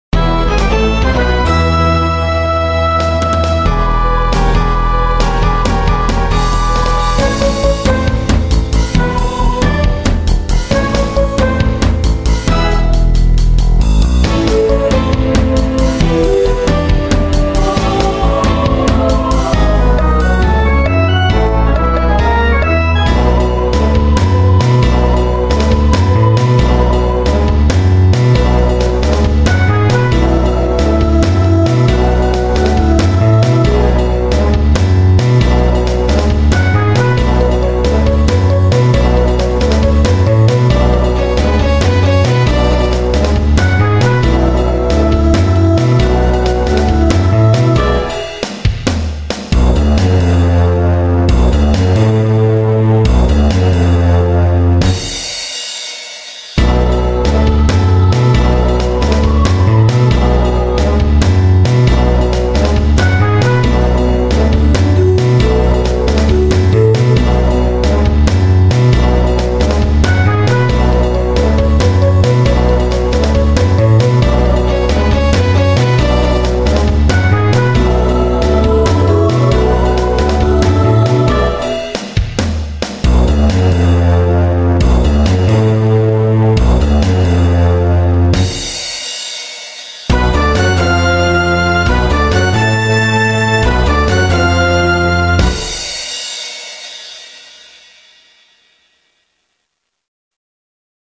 The music is scored for a 16 piece orchestra and at times has 9 individual characters singing together on stage.
Please excuse the vocals on “The Bright Life” tunes being computerized ohs and ahs.
The Bright Life 2-2 Where’s The Cash Musical Theater